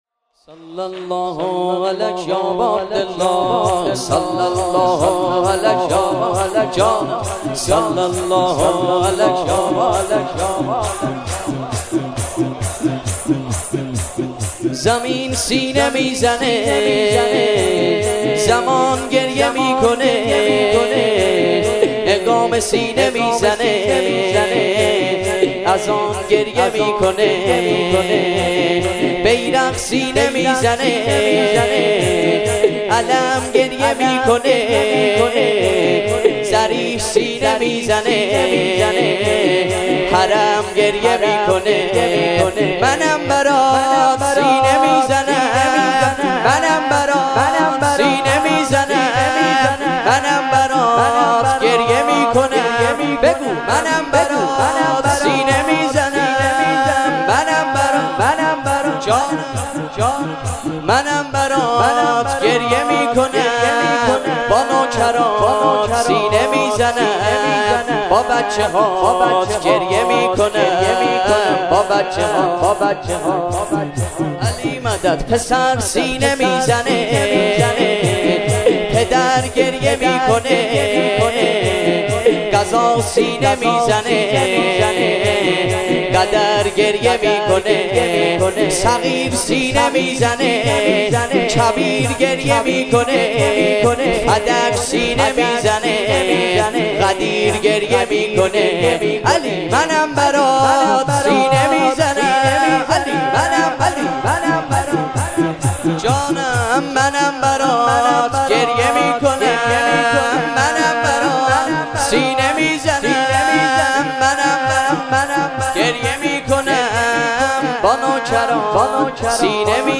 مراسم روضه اسارت اهل بیت (ع)- مرداد 1401
مداحان:
شور- زمین سینه میزنه، زمان گریه میکنه